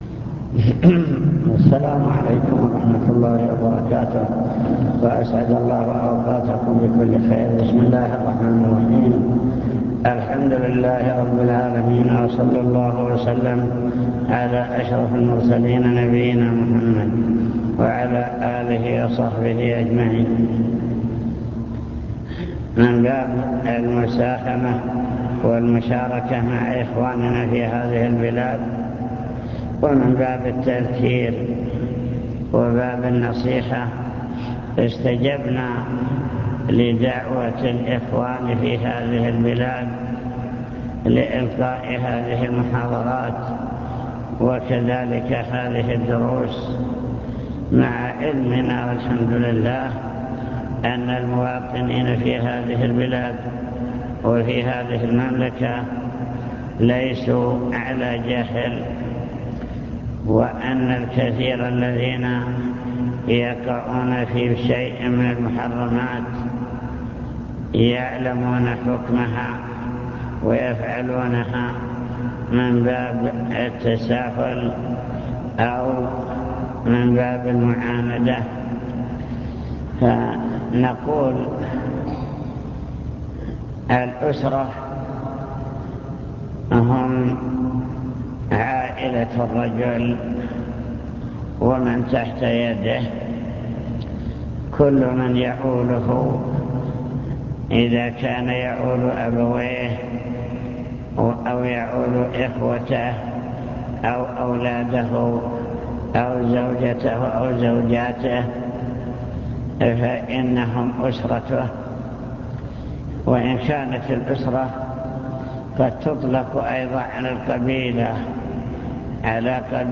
المكتبة الصوتية  تسجيلات - محاضرات ودروس  محاضرة واجب المسلم نحو أسرته واجب الرجل نحو أسرته في الأمور الدنيوية